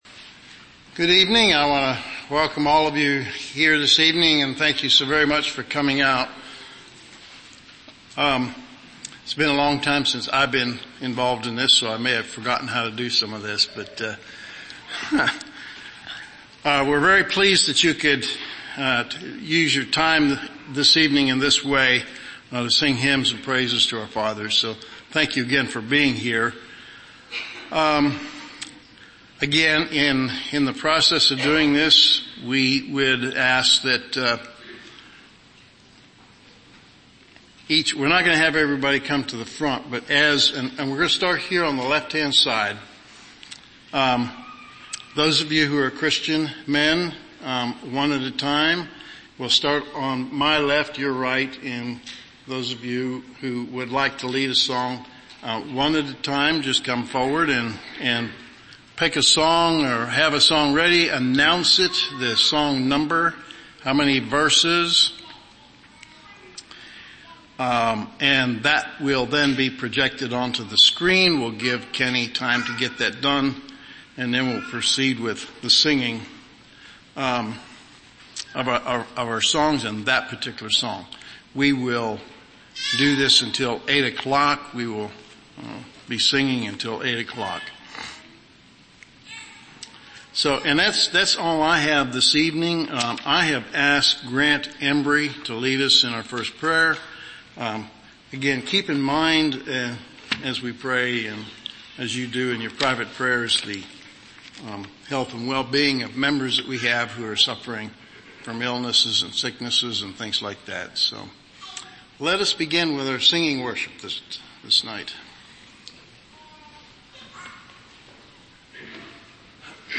3rd Friday Hymn Singing – October 18, 2013 – By Song Leaders
Friday_Singing_3-19-21.mp3